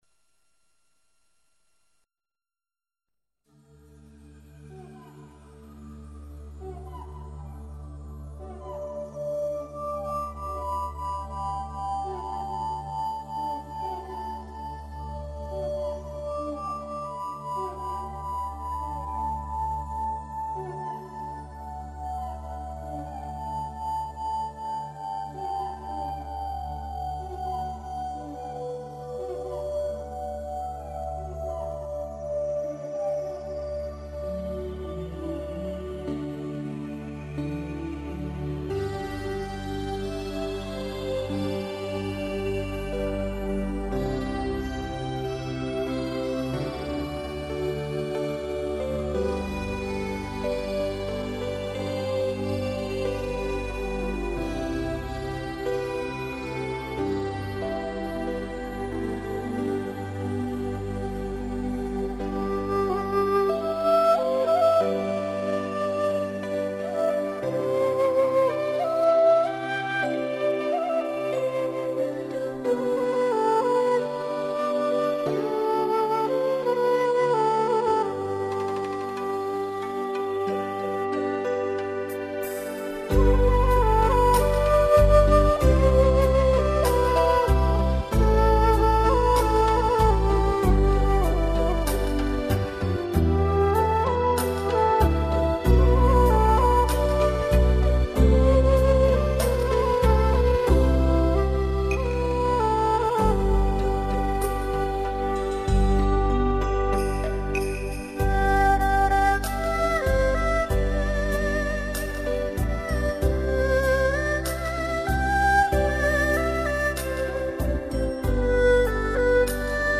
这张民乐挺有意思的，这里有[古筝伴奏小提琴]，真正洋为中用了，古筝代替了竖琴。
以民乐为主的独奏和重奏，加上小提琴，以及动人的西藏音乐旋律，